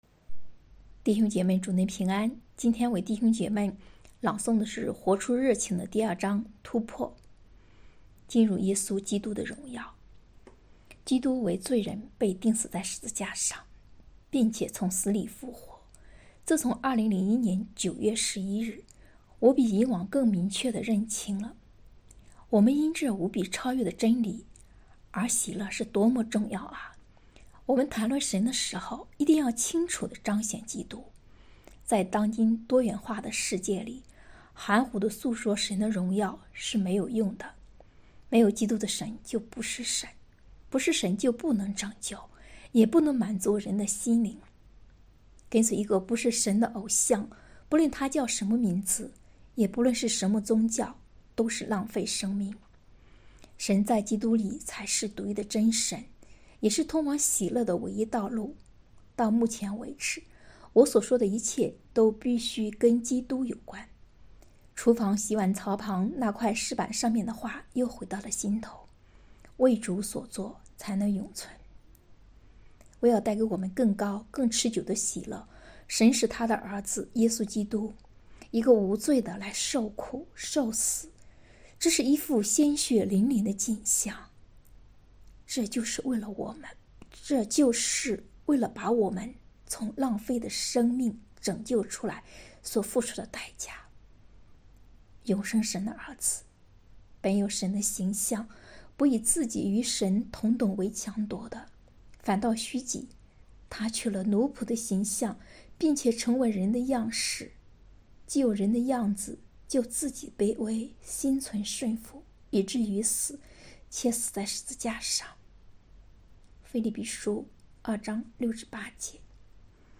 2023年7月13日 “伴你读书”，正在为您朗读：《活出热情》 本周章节： 作者：约翰·派博（John Piper） 译者：张书筠 别浪费生命！活出热情的人生！